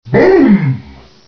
Bboom.ogg